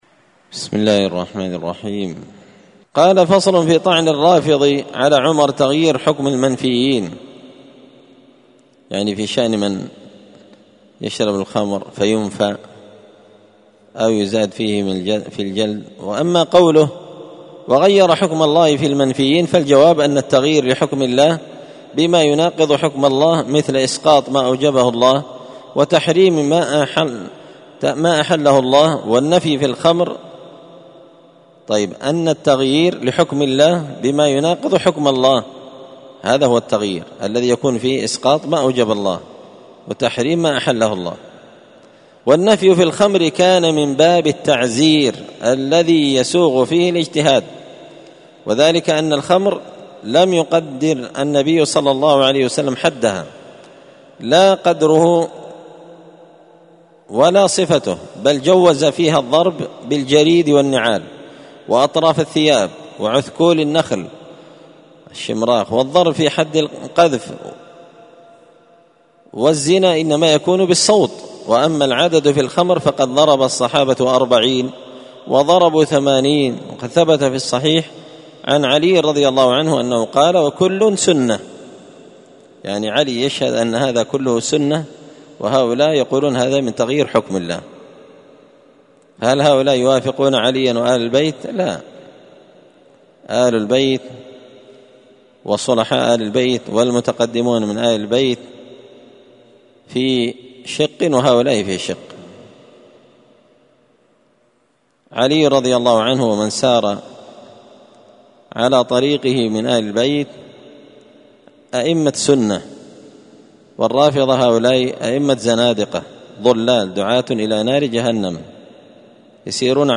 الأربعاء 3 ذو الحجة 1444 هــــ | الدروس، دروس الردود، مختصر منهاج السنة النبوية لشيخ الإسلام ابن تيمية | شارك بتعليقك | 9 المشاهدات
تعليق وتدريس الشيخ الفاضل: